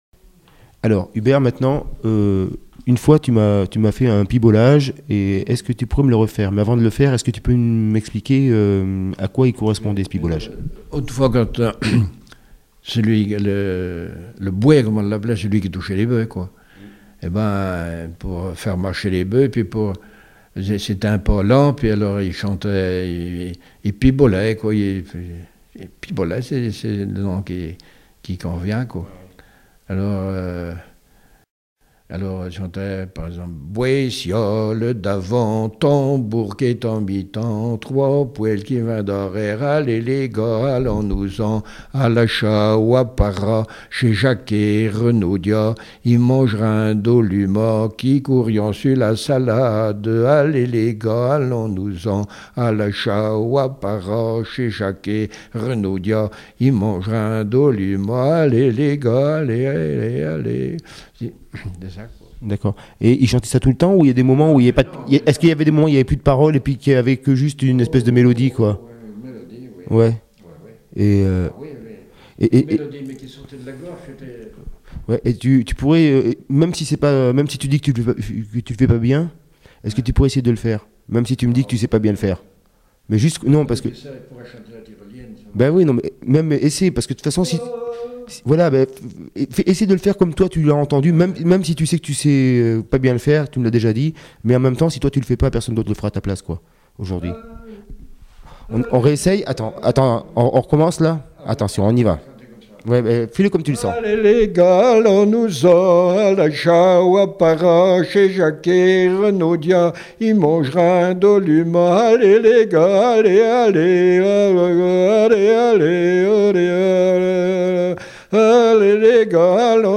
Appels de labour, tiaulements, dariolage, teurlodage, pibolage
Arondage, briolage
couplets vocalisés
Pièce musicale inédite